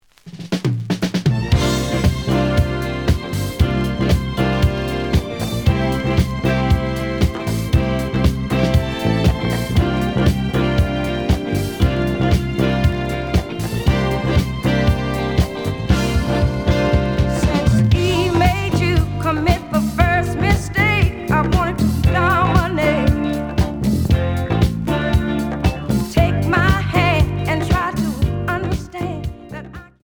(Mono)
試聴は実際のレコードから録音しています。
●Genre: Disco